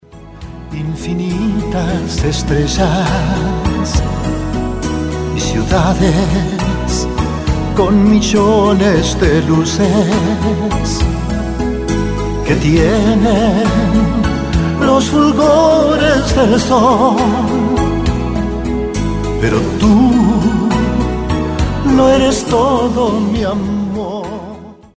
Schmuse-Schlager spanisch gesungen